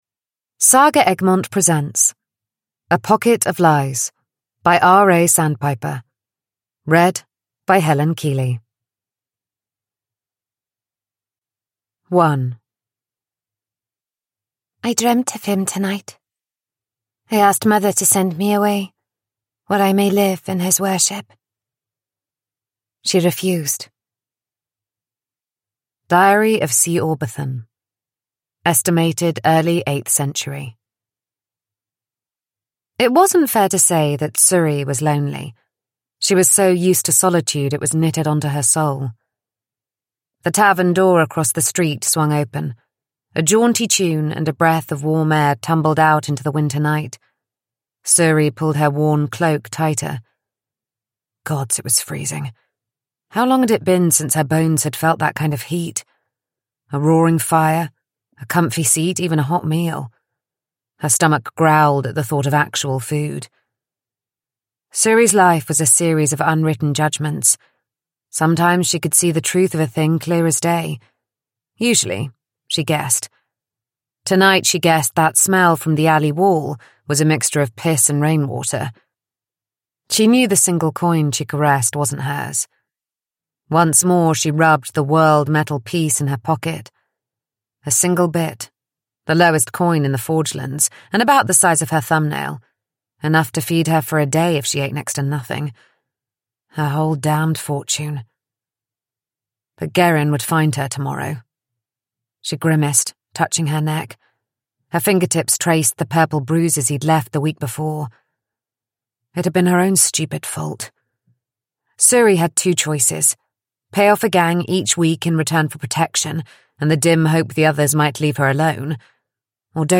A Pocket of Lies: a fast-paced slow-burn fantasy romance – Ljudbok